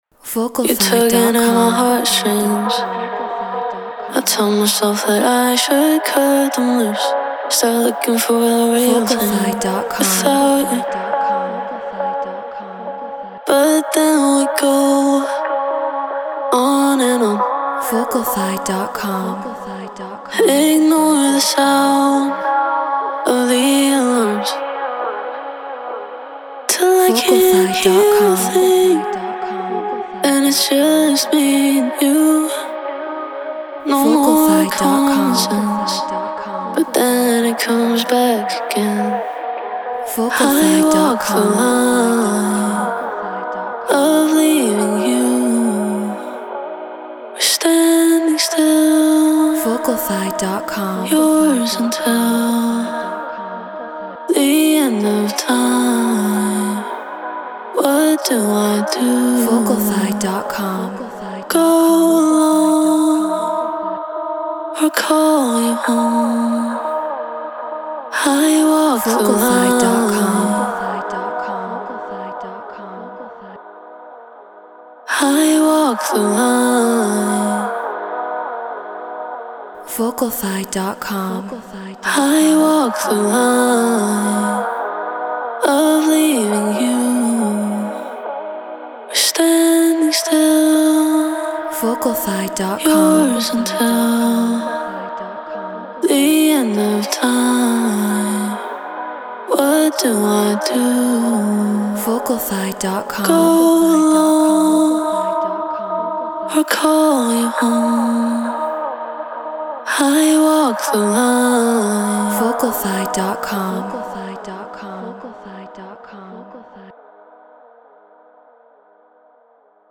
Trance 138 BPM G#min
Shure SM7B Apollo Solo Logic Pro Treated Room